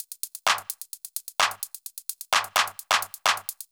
CLF Beat - Mix 20.wav